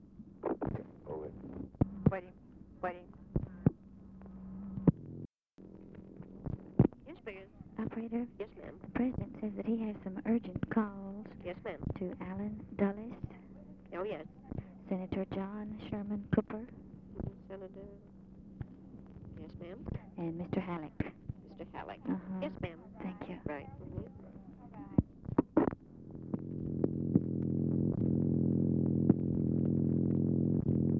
SECRETARY ASKS OPERATOR TO PLACE TELEPHONE CALLS
Secret White House Tapes